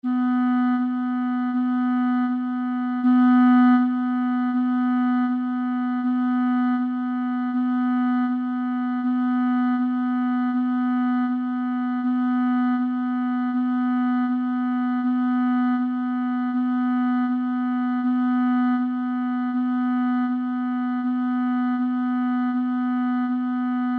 Velaquí atoparedes os arquivos de audio coas notas da escala musical:
Nota SI